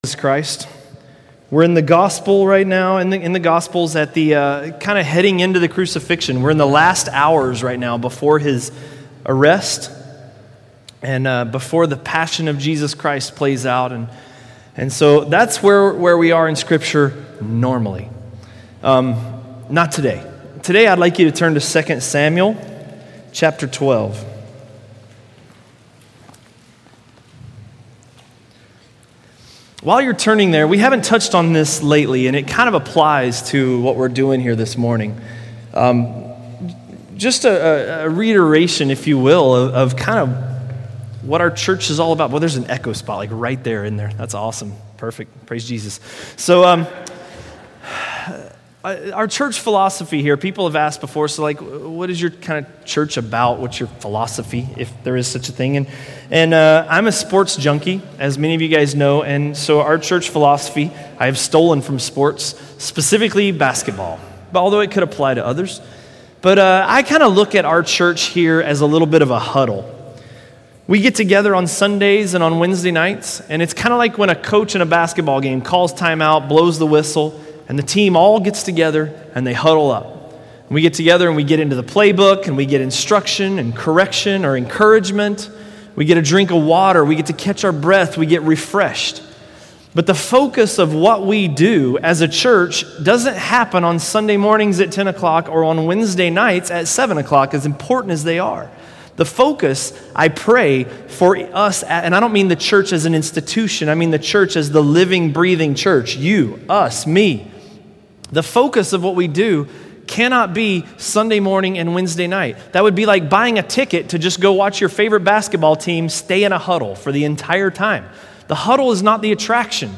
A message from the series "2 Samuel." 2 Samuel 12:1–12:6